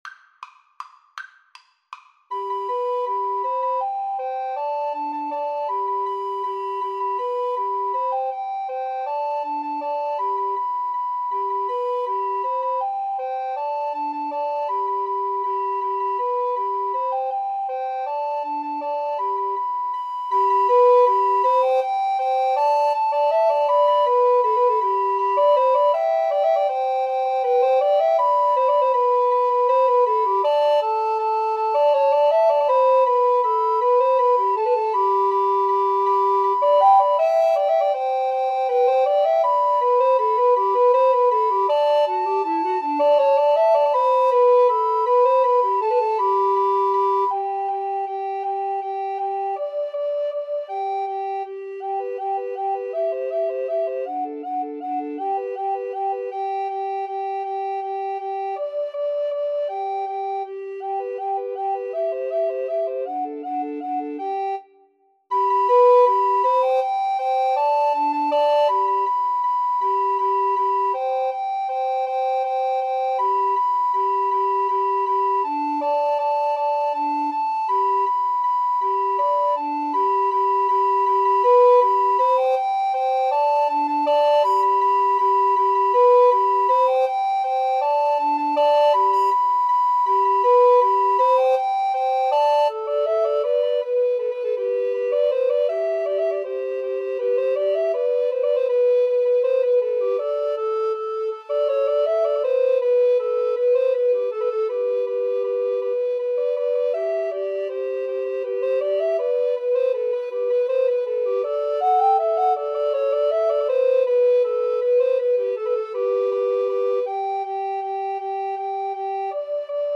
Allegro Vivo = 160 (View more music marked Allegro)
Recorder Trio  (View more Intermediate Recorder Trio Music)
Classical (View more Classical Recorder Trio Music)